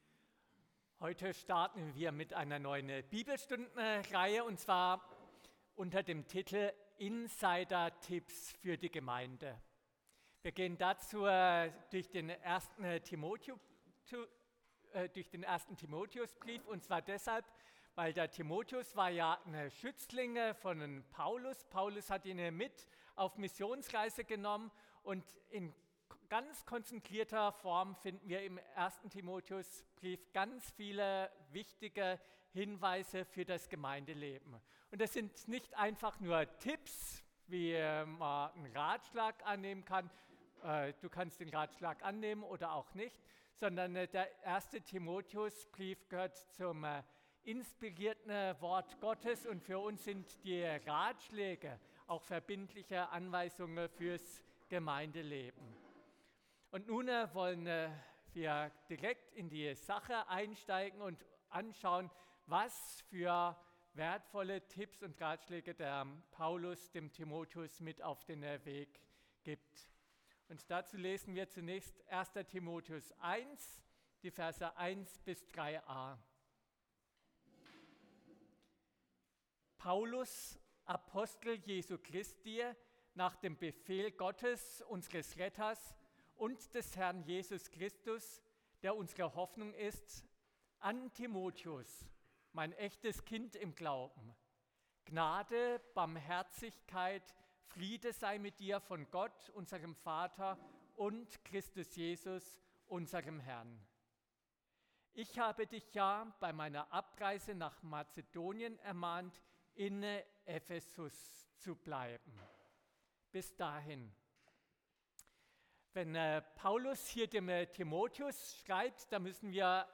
Predigt Download